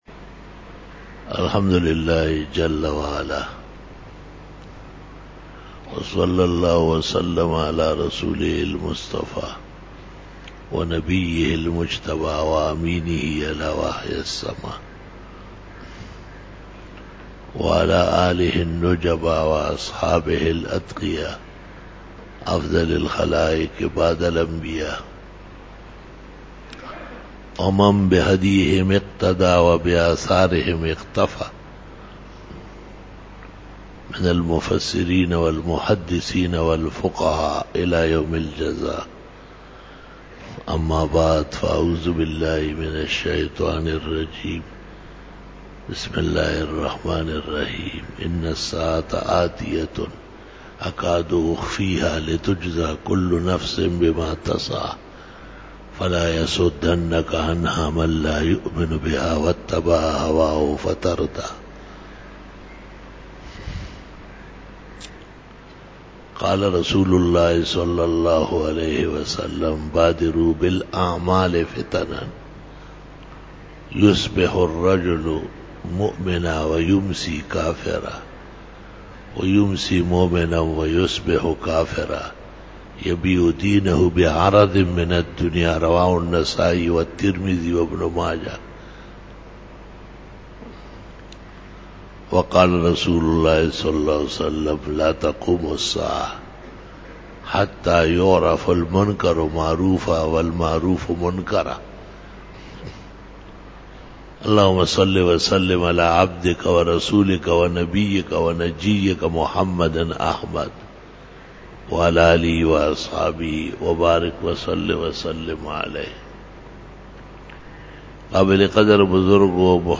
06 BAYAN E JUMA TUL MUBARAK (09 FEBRUARY 2018) (22 Jamadi ul Awwal 1439H)